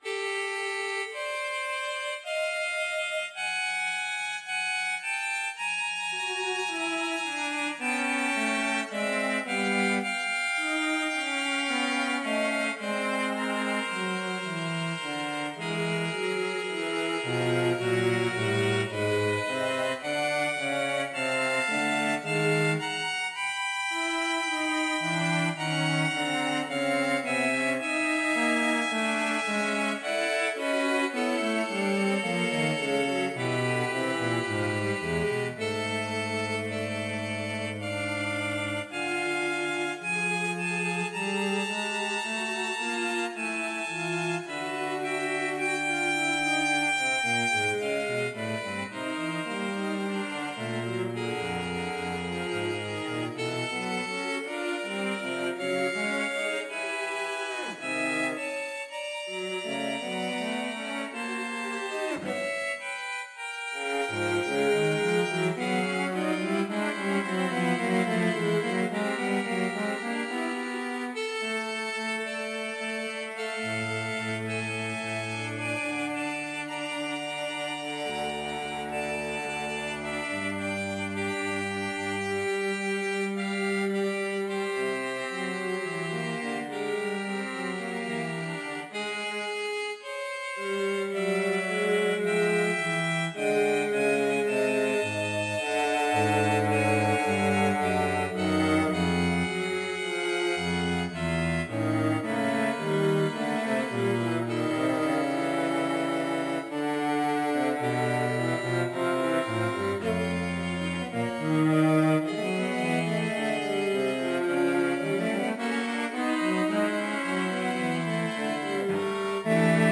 1982 Sequence mp3 created from the Notion Score. PDF Score Violin 1 PDF Part Violin 2 PDF Part Viola PDF Part Cello PDF Part My favorite classical ensemble is the string quartet.
For my second String Quartet effort, I chose this theme for its minor key modality, the classic balance of steps and skips, and very interesting chromatic movement at the crest of the melodic line.
The quartet is continuous with short fermatas between sections. The mp3 recording is made from a Notion file and is a sequence of the score using artificial instruments and mechanized playback. There are missing time changes, such as fermatas and rubatos, that will make the transitions seem more abrupt than would occur with human playing.
String_Quartet_2.mp3